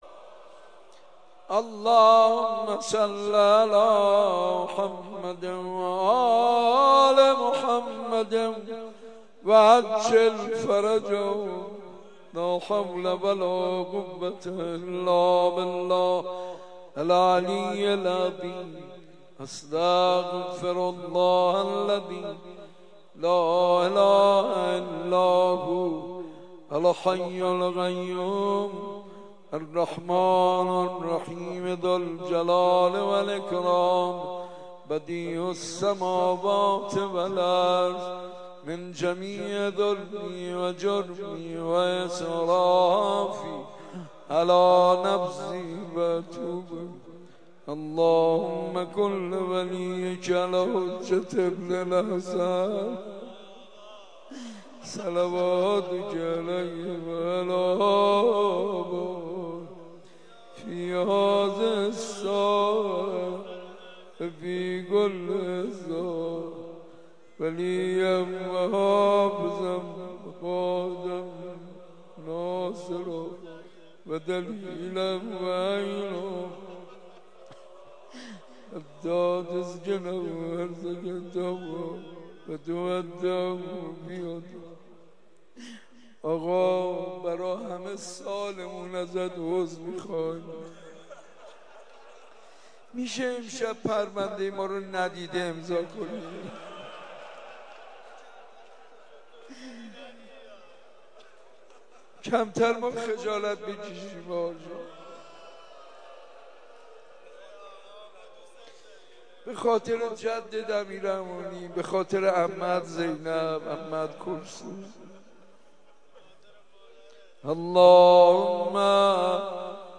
مراسم شب نوزدهم ماه مبارک رمضان در مسجد ارک تهران با صدای حاج منصور ارضی.